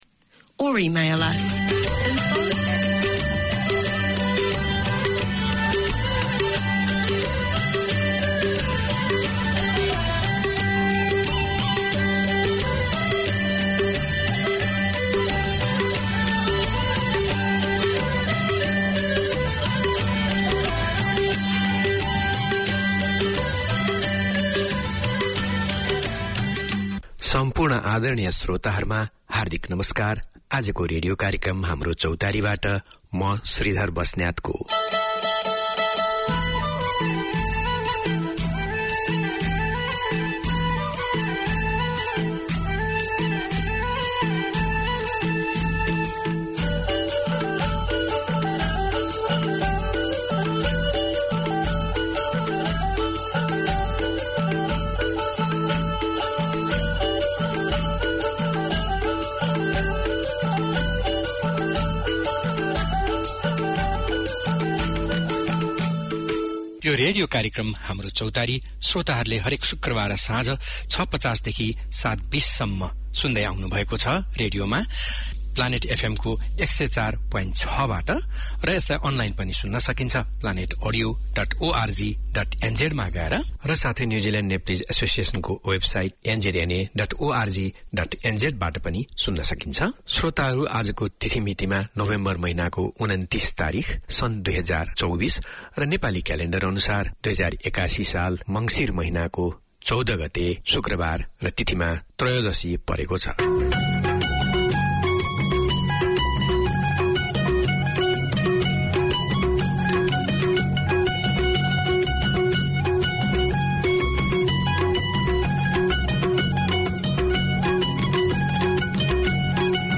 Radio made by over 100 Aucklanders addressing the diverse cultures and interests in 35 languages.